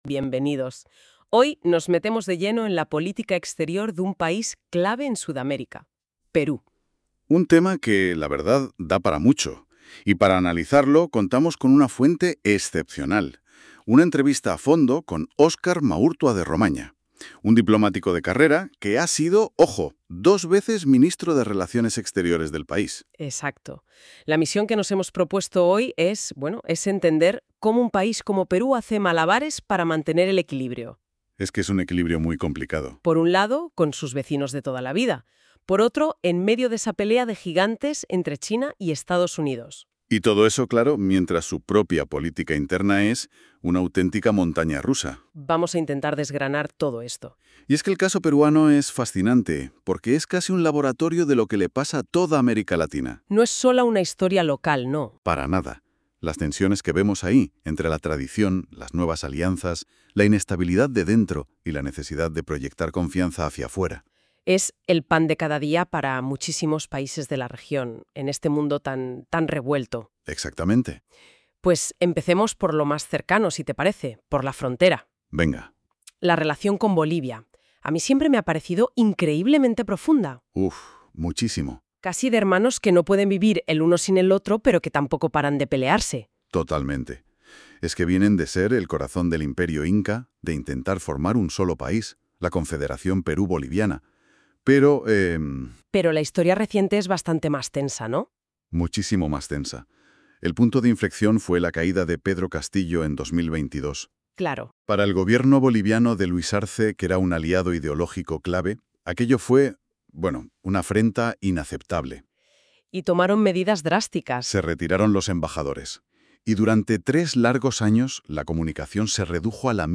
Oscar Maurtua de Romaña, claves para leer la política exterior peruana hoy. Entrevista y Podcast